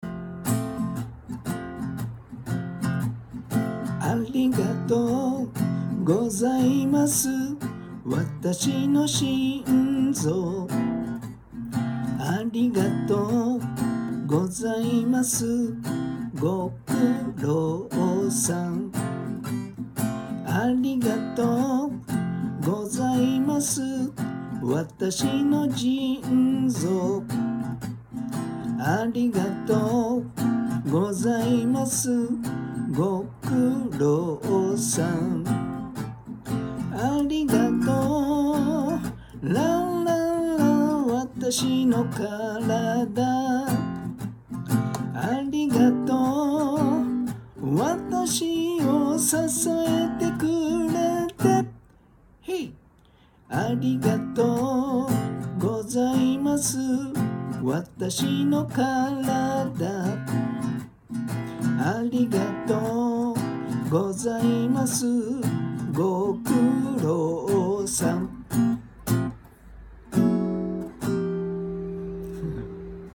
軽やかな曲でしょ。